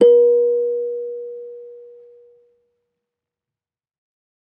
kalimba1_circleskin-B3-mf.wav